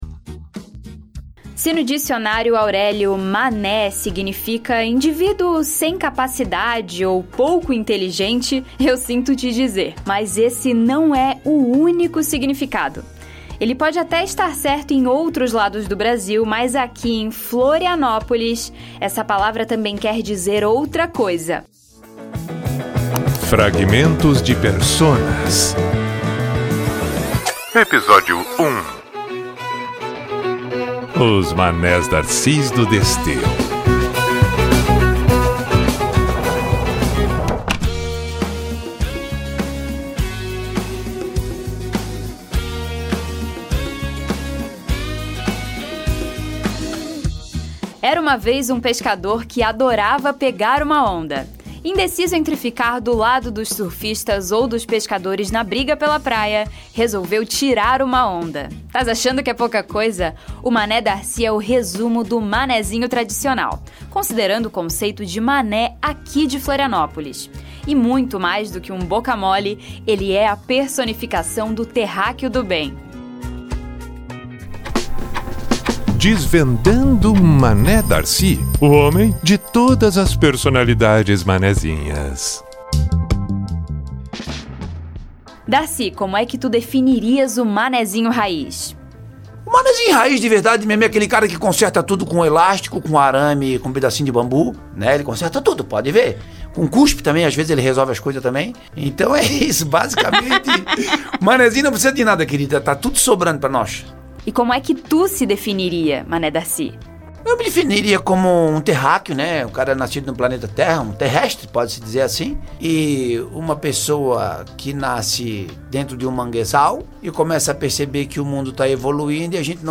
São perfiladas pessoas que se encaixam nas características pescador, anedotista e músico. A apuração foi desenvolvida através de entrevistas e pesquisas online, oferecendo uma visão divertida e autêntica das comunidades.